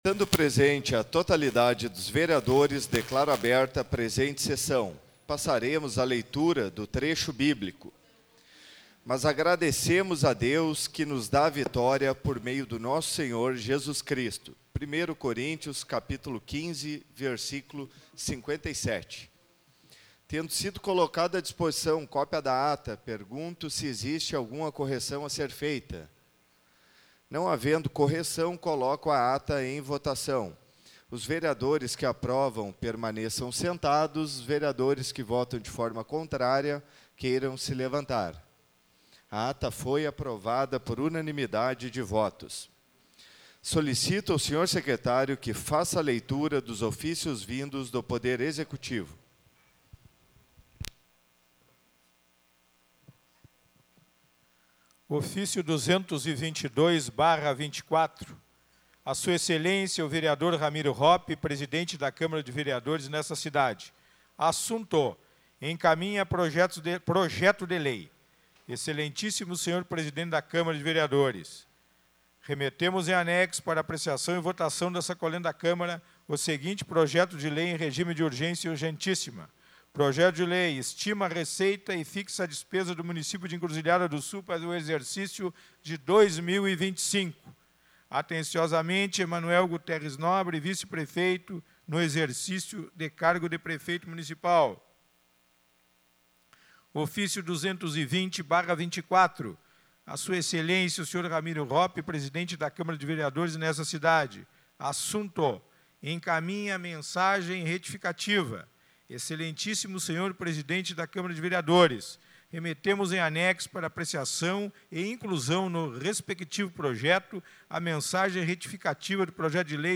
Áudio Sessão 02.12.2024 — Câmara de Vereadores